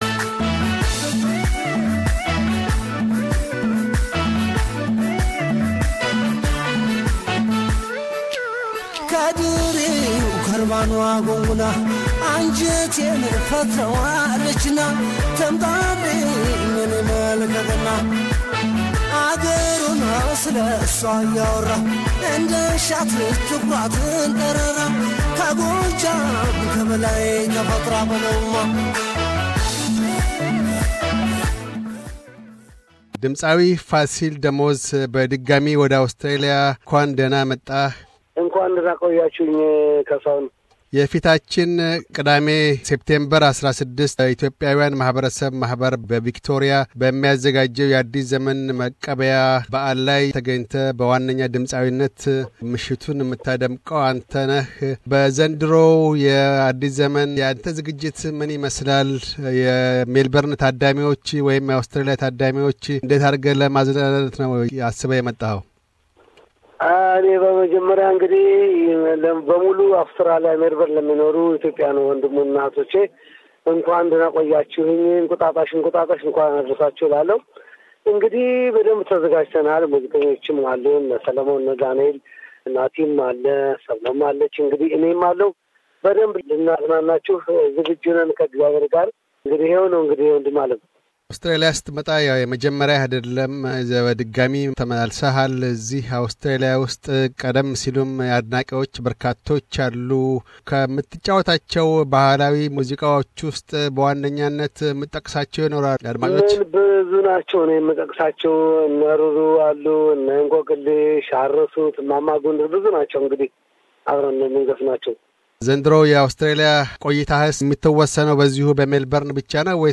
Interview with Singer Fasil Demoz